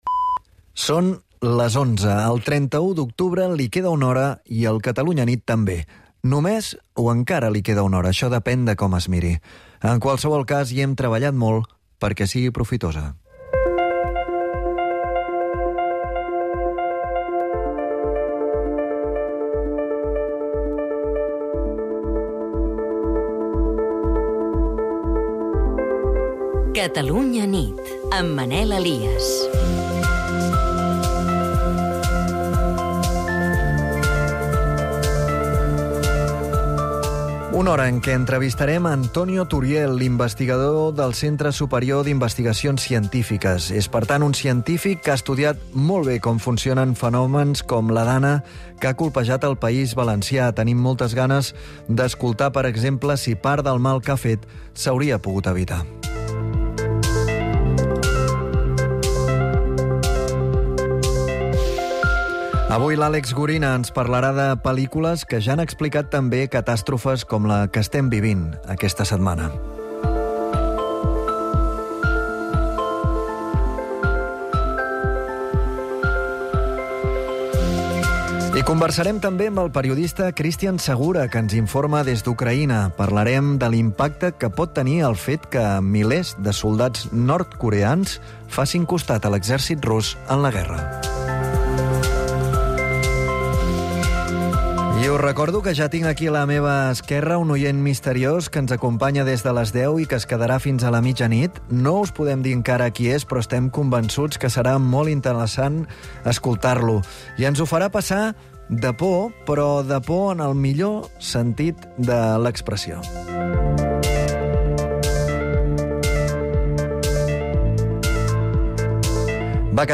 Reproduir episodi Veure més episodis del programa Catalunya vespre RSS feed Veure més episodis del programa Catalunya vespre Veure altres programes de la categoria informatius